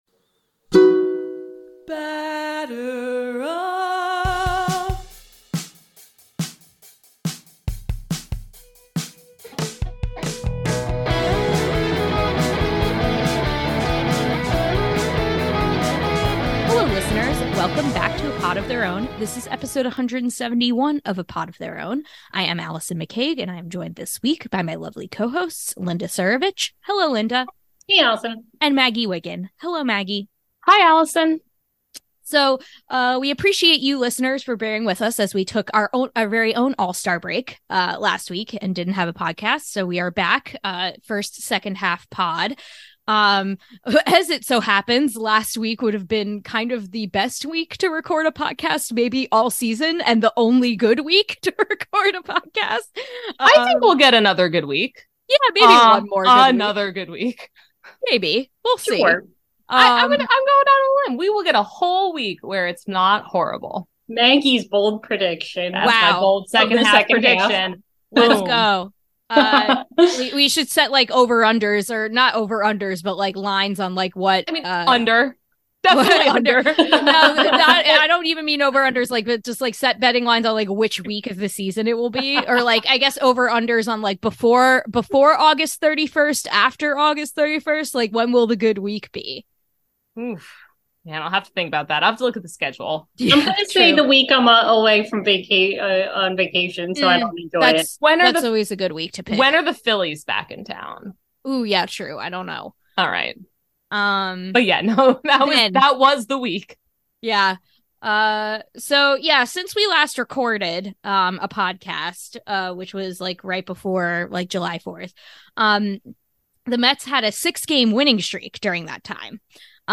Welcome back to A Pod of Their Own, an all-women led Home Run Applesauce podcast where we talk all things Mets, social justice issues in baseball, and normalize female voices in the sports podcasting space.
In the second half of the show, we have some our White Sox friends on to commiserate about rooting for underwhelming teams and rooting for teams that willingly employ abusers.